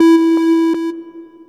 Tritone.wav